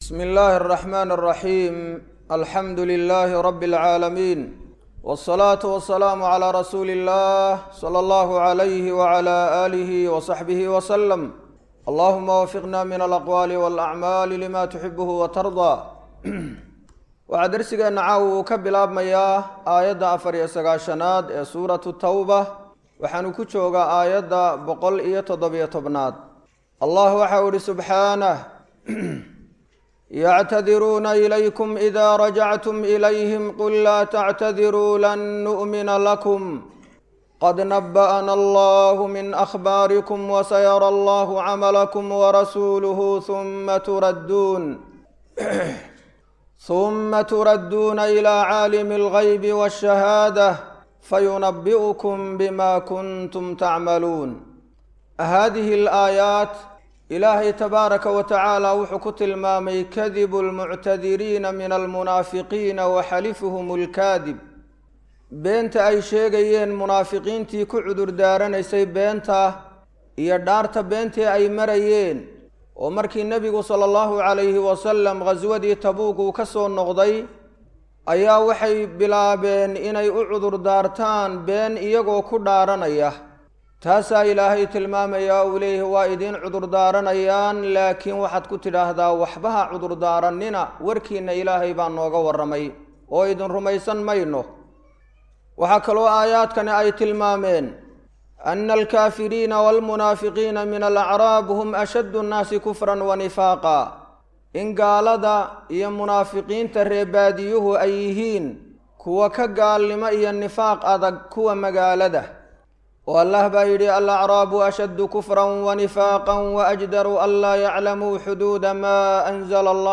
Waa Tafsiir Kooban Oo Ka Socda Masjid Ar-Rashiid – Hargaisa